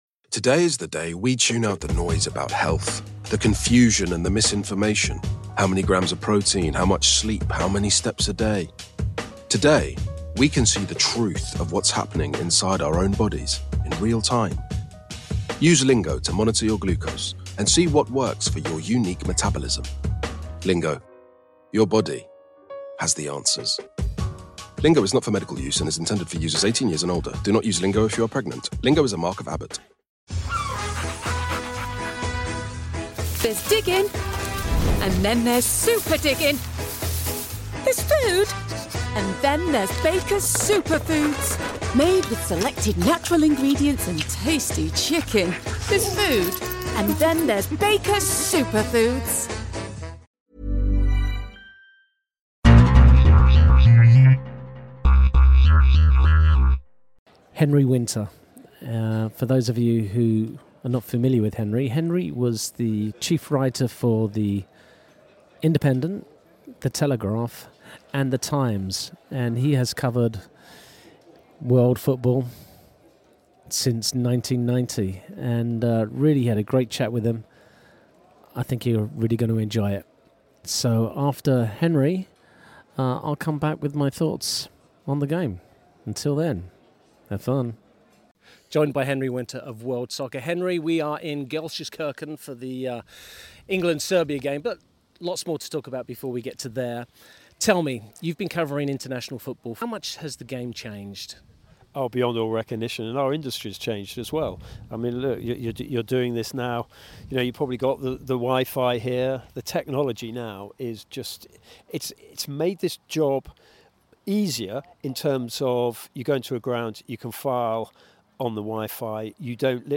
Henry Winter interview at Euro 2024